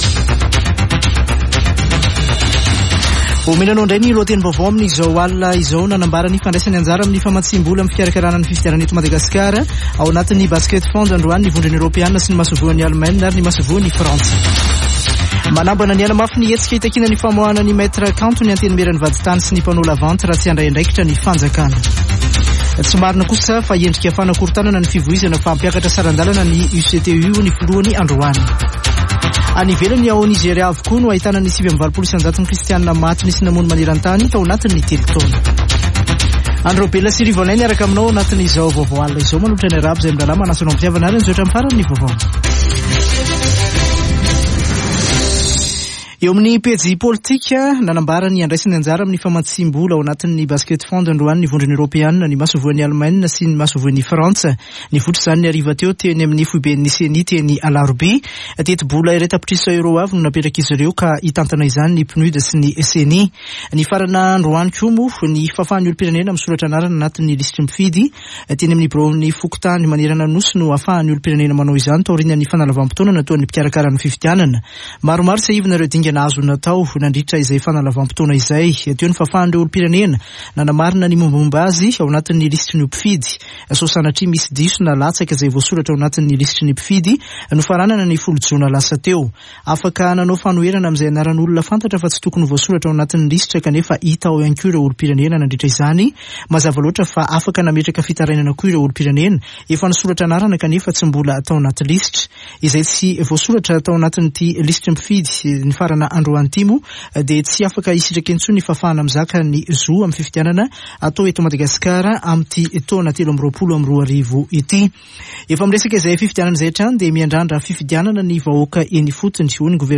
[Vaovao hariva] Alakamisy 6 jolay 2023